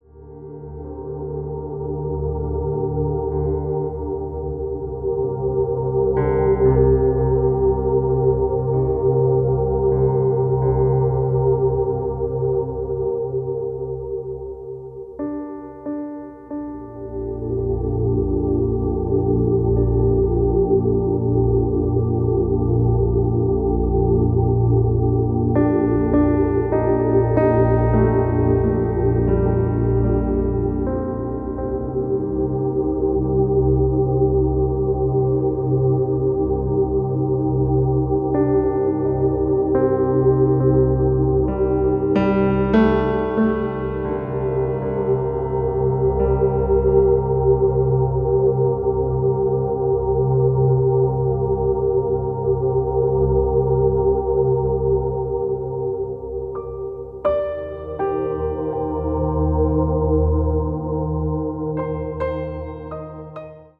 House Breaks